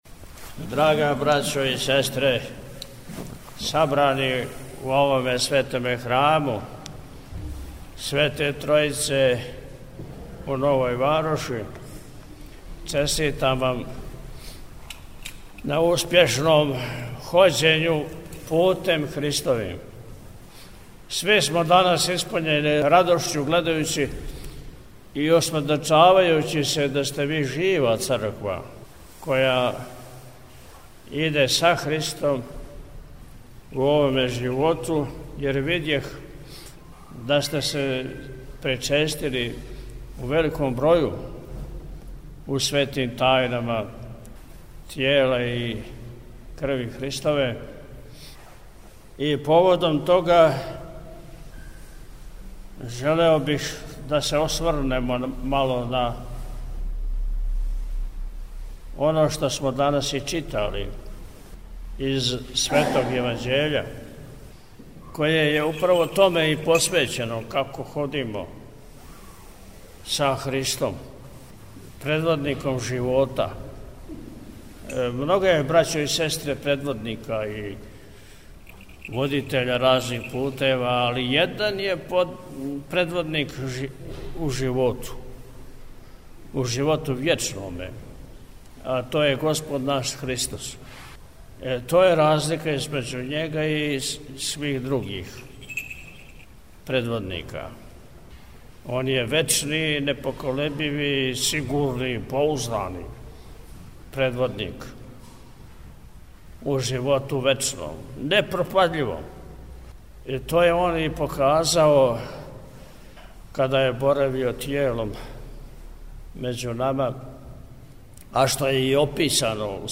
Владика је након отпуста, пригодном пастирском беседом, поучио верни народ, рекавши, између осталог: – Сви смо данас испуњени радошћу гледајући и осведочавајући се да сте ви Жива Црква која иде са Христом у овоме животу, јер видех да сте се причестили у великом броју, у Светим Тајнама Тела и Крви Христове.